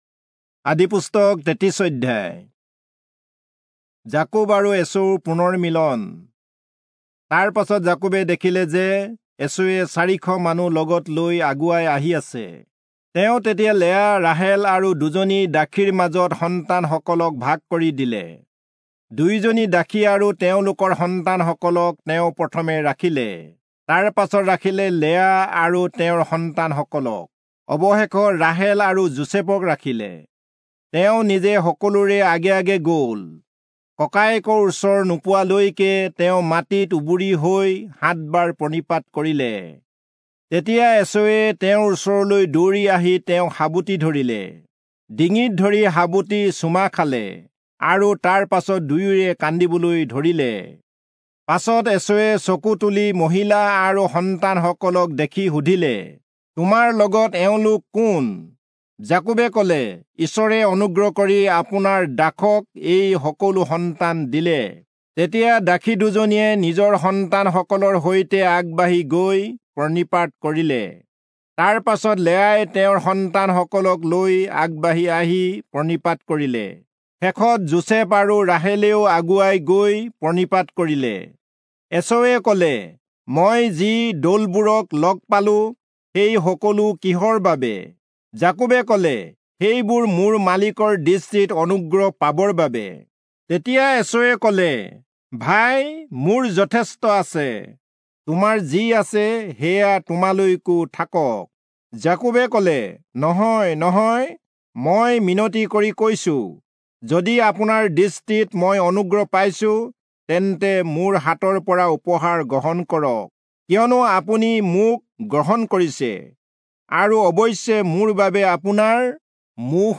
Assamese Audio Bible - Genesis 11 in Kjv bible version